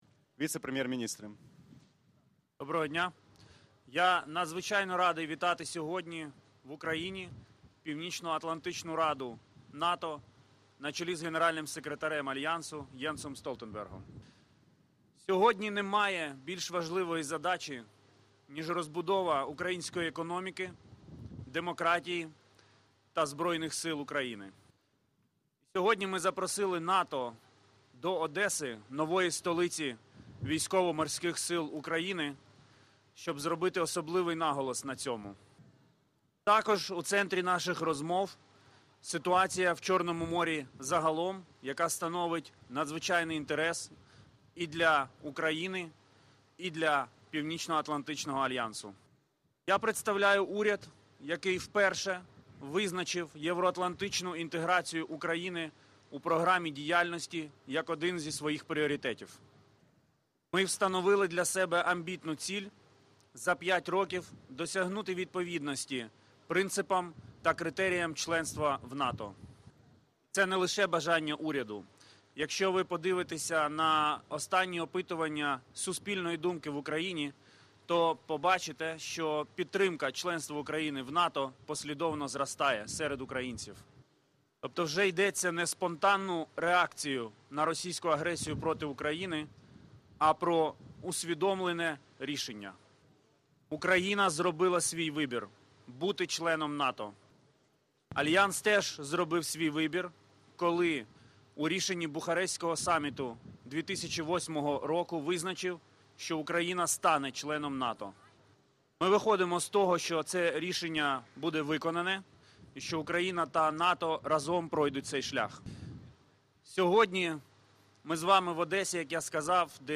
Joint press point with NATO Secretary General Jens Stoltenberg and the Vice Prime Minister of Ukraine for European and Euro-Atlantic Integration, Dmytro Kuleba